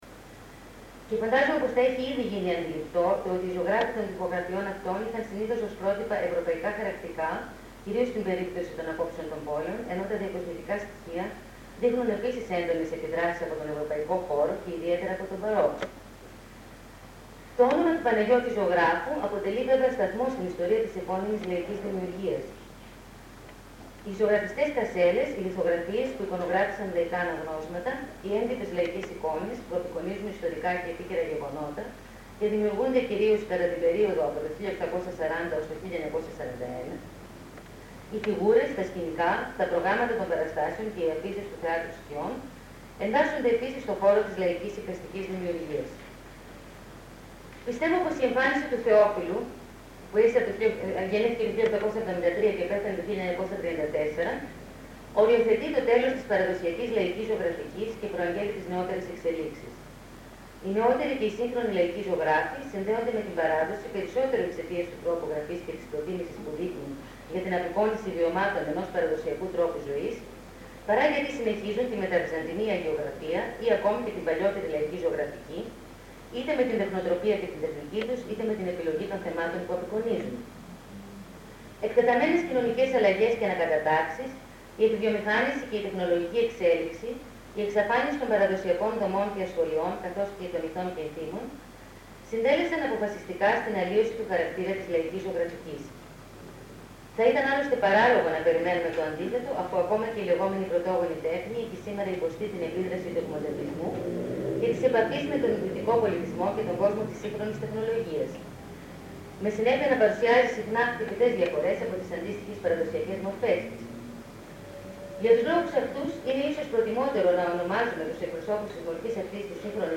Κύκλος ομιλιών στο ΛΕΜΜ-Θ.
Β' κύκλος ομιλιών. (EL)